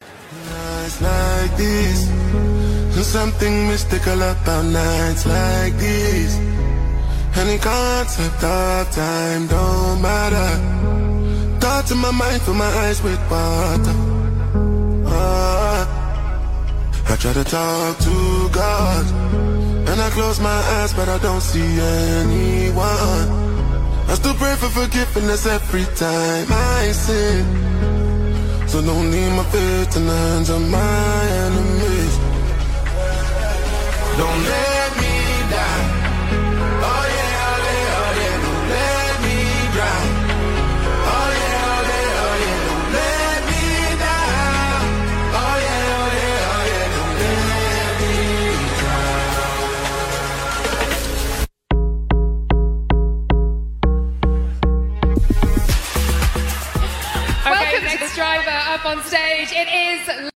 Afrofusion
deeply emotional new track